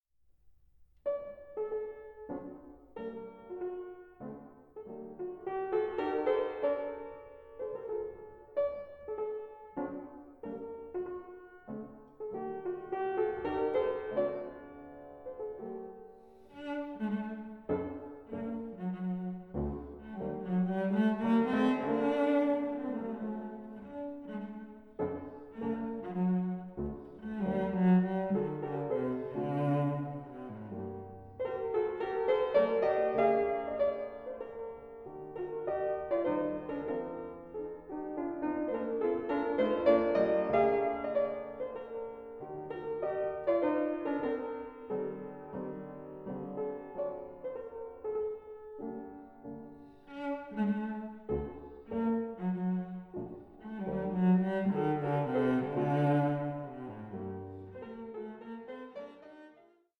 1837 Érard fortepiano